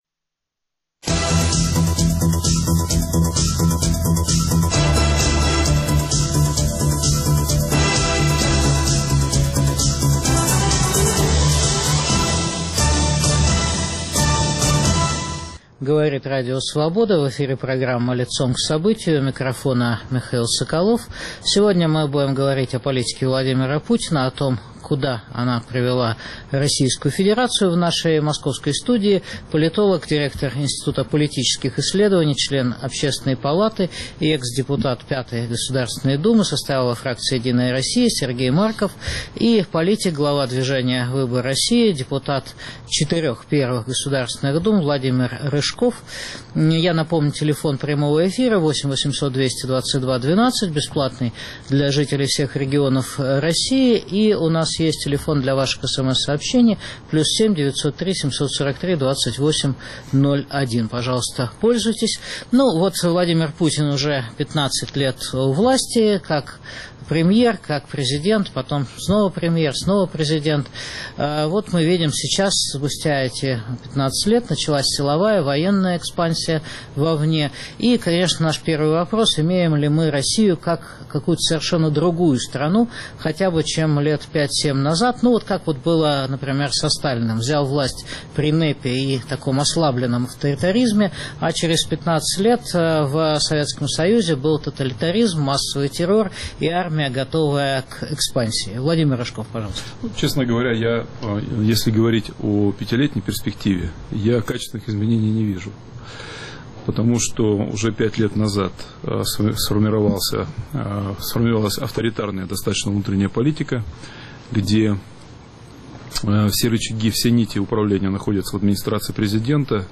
О политике Путина дискутируют Сергей Марков и Владимир Рыжков.